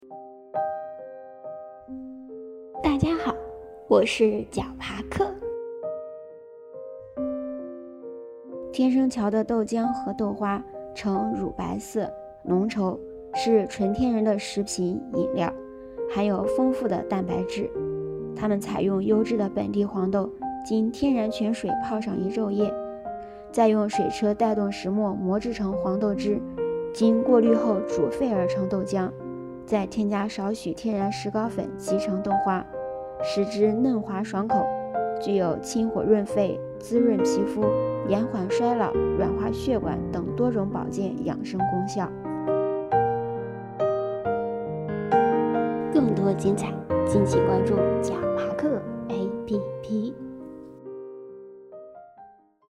豆坊----- 豌豆荚 解说词: 天生桥豆浆、豆花，成乳白色、浓稠，是纯天然的食品、饮料，含有丰富的蛋白质。